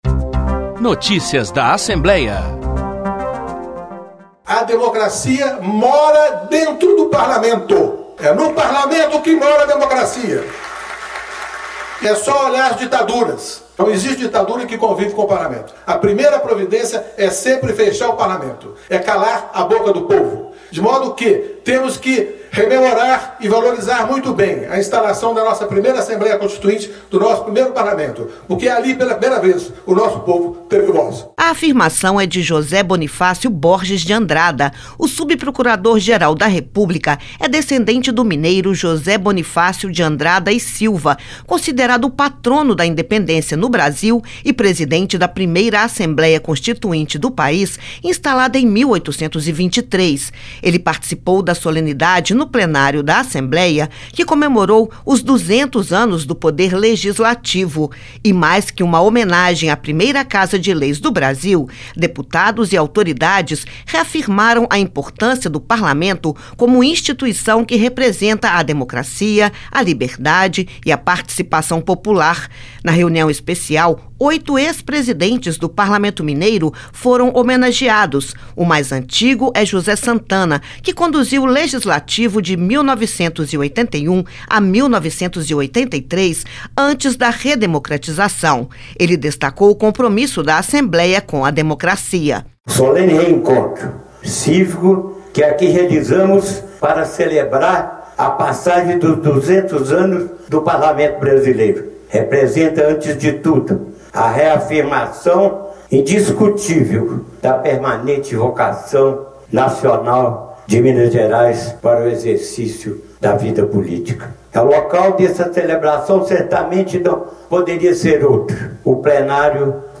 Na voz de diversos atores que fizeram a história do Poder Legislativo de Minas, a instalação da primeira Assembleia Constituinte, em 1823, é relembrada e valorizada como caminho para dar voz ao povo.